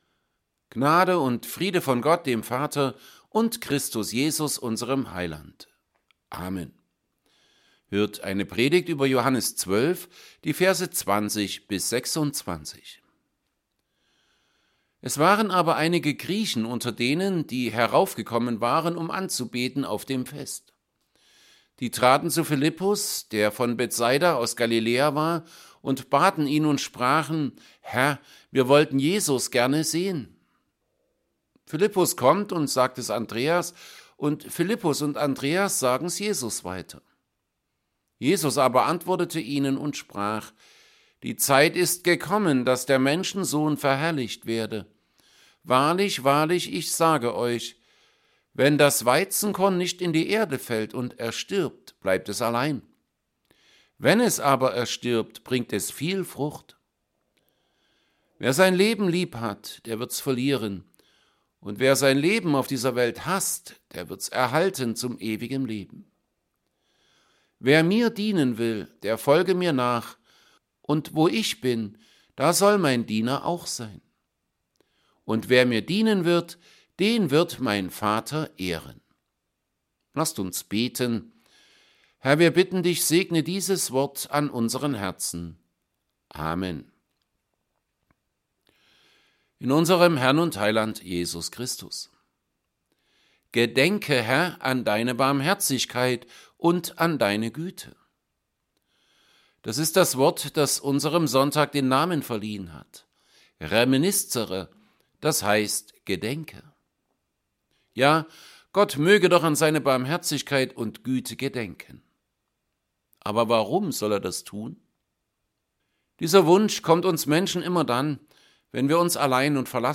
Evangelienpredigten Sonntag Reminiszere 25. Februar 2024 Jesu Gleichnis führt uns zur Buße!